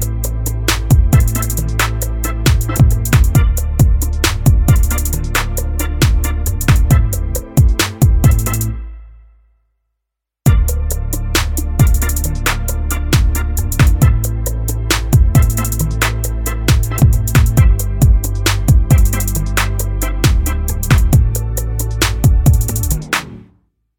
no Backing Vocals R'n'B / Hip Hop 4:09 Buy £1.50